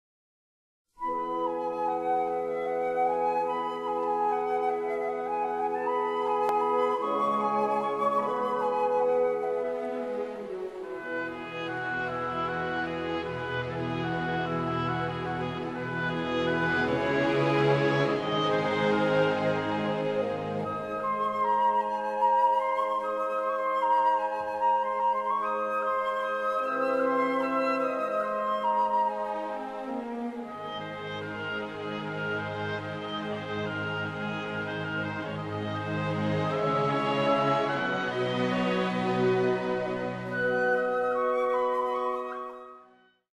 01_amanecer.mp3